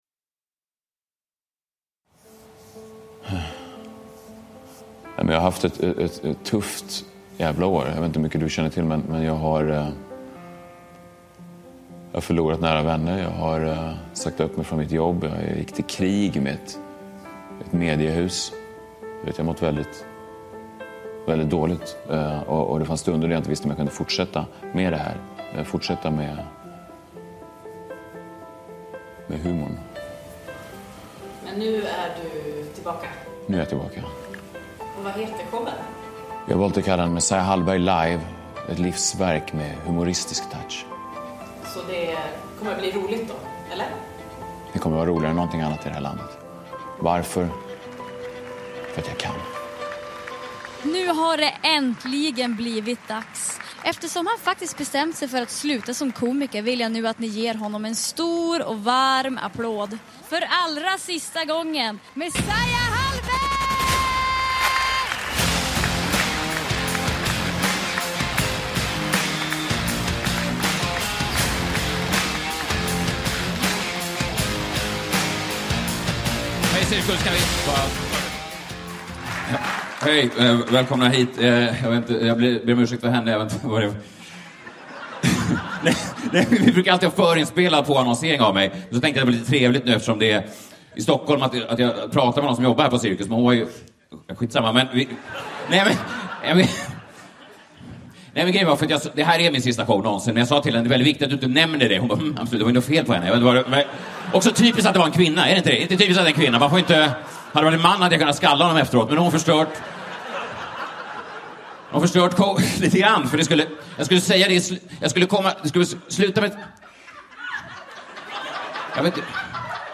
Ett livsverk med humoristisk touch – Ljudbok
Uppläsare: Messiah Hallberg